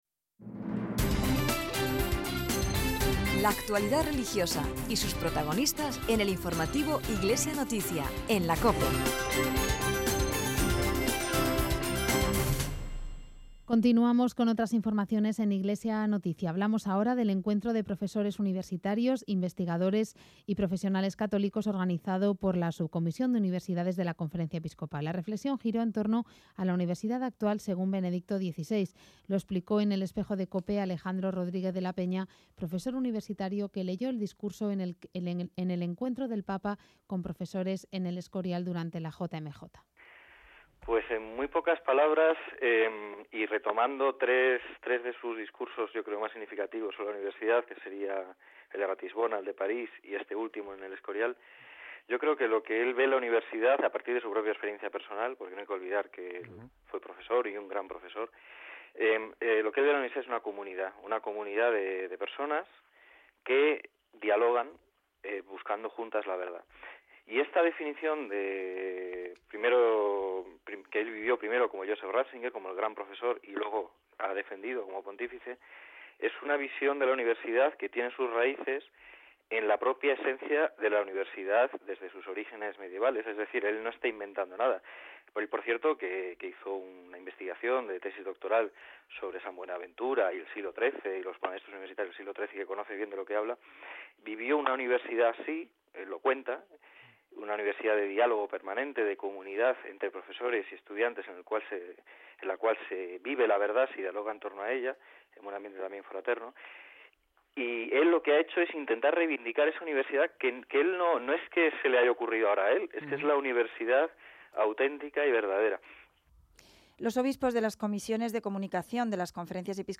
el testimonio
en el Programa Iglesia Noticia de COPE del pasado 1 de julio